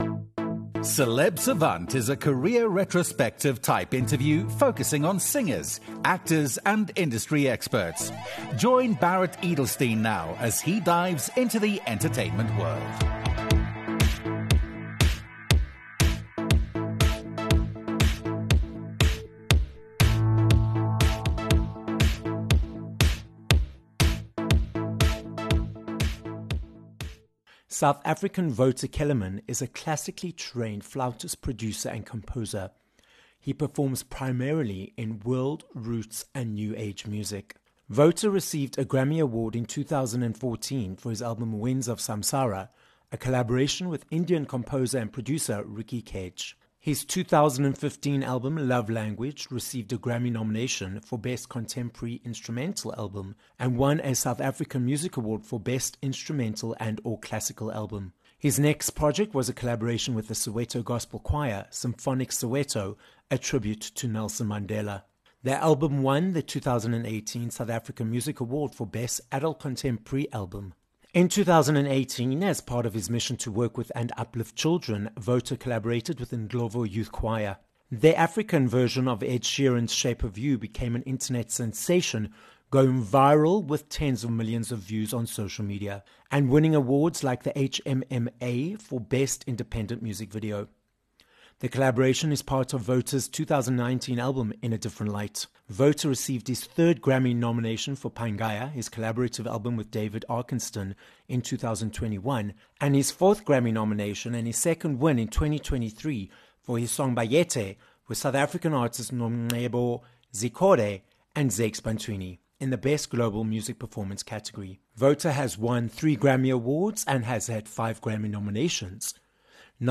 Wouter Kellerman - a South African flautist, producer, composer, 3×Grammy and 9xSAMAs awards winner - joins us live in studio on this episode of Celeb Savant. Wouter explains how he started his professional career in engineering and how, after 20 years at the age of 44, he went into music full-time... successfully creating award-winning World, Roots, and New Age Music.
This episode was recorded live in studio at Solid Gold Podcasts, Johannesburg, South Africa.